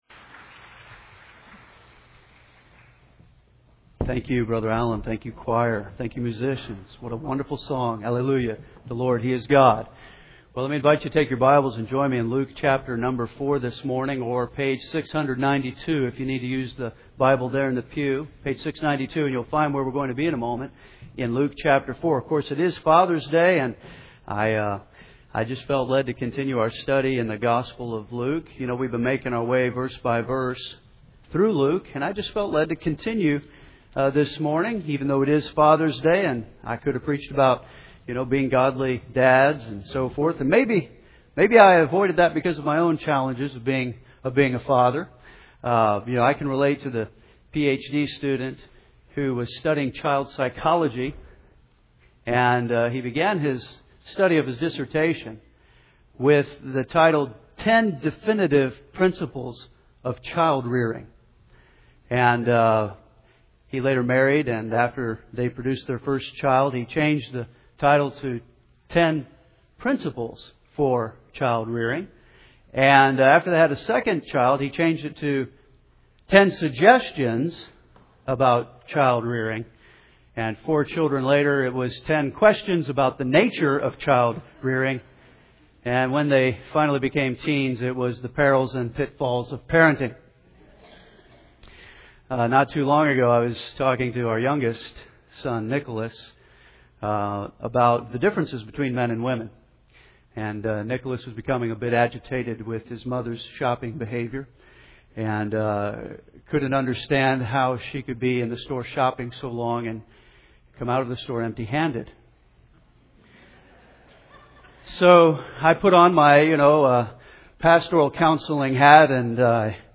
Take your Bibles and open them to Luke, chapter 4.